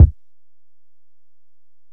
Kick (5).wav